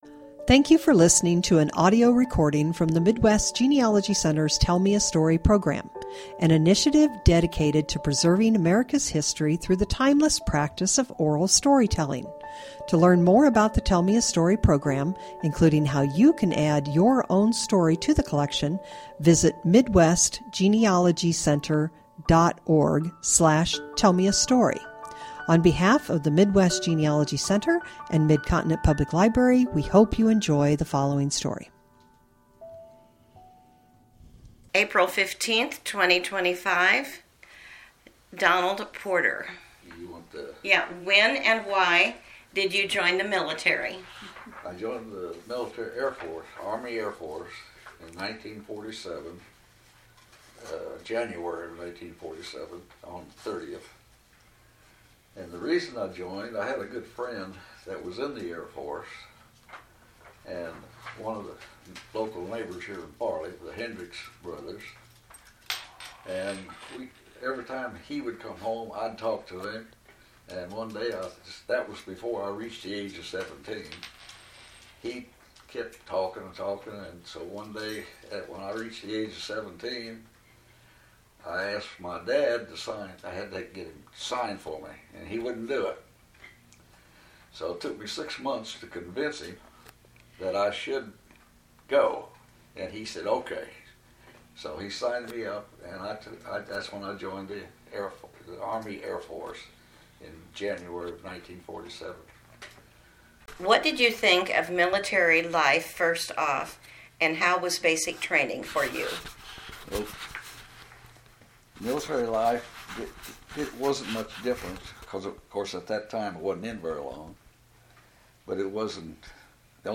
Oral History Genealogy Family Stories